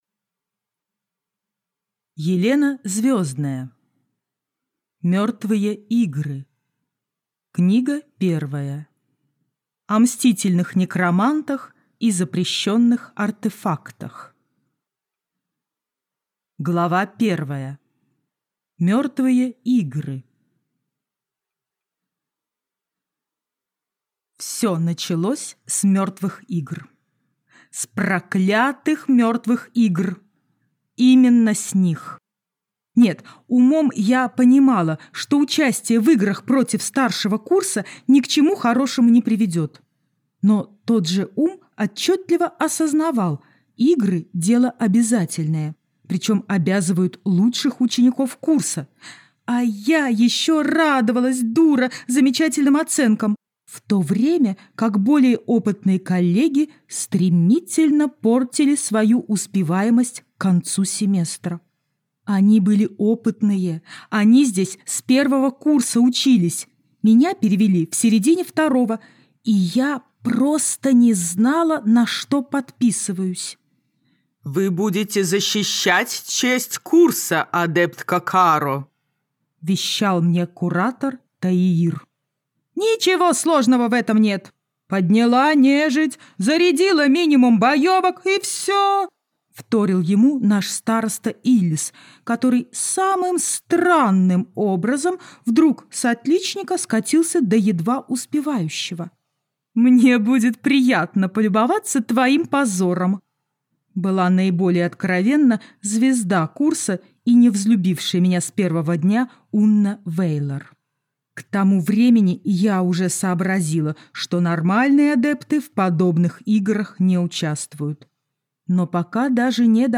Аудиокнига О мстительных некромантах и запрещенных артефактах - купить, скачать и слушать онлайн | КнигоПоиск